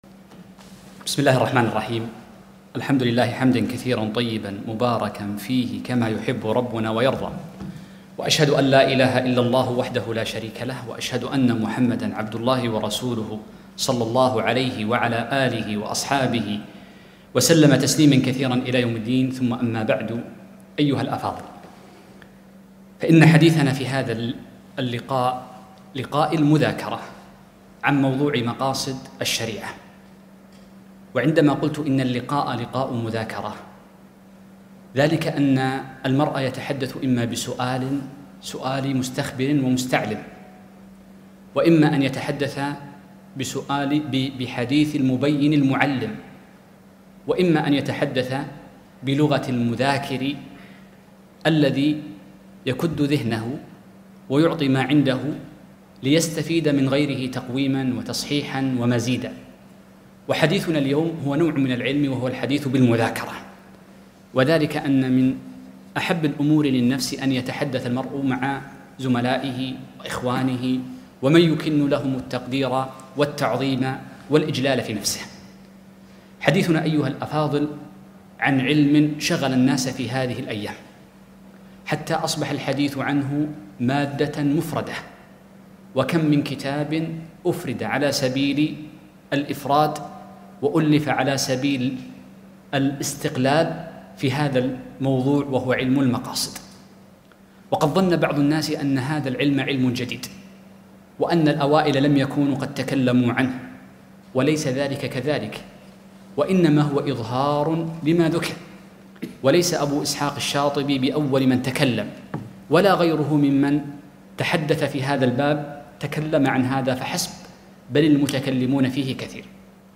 محاضرة - ضوابط في فهم مقاصد الشريعة